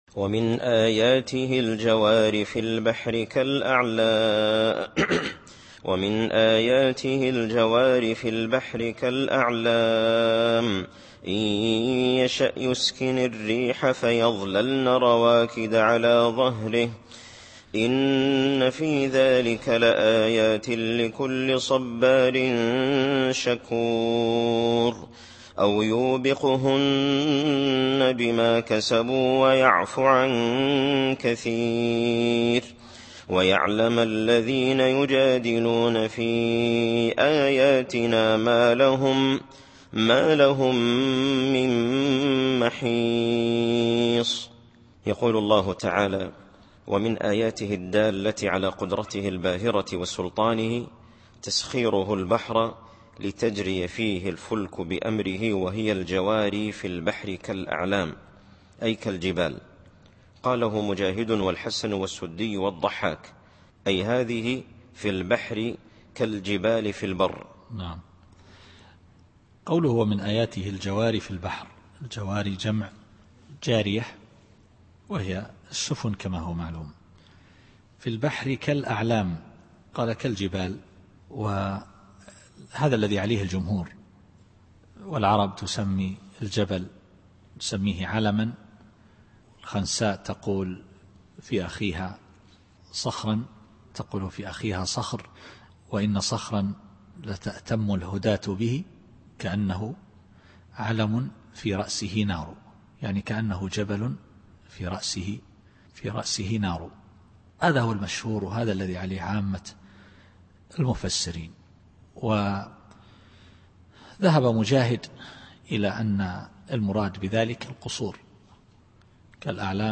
التفسير الصوتي [الشورى / 32]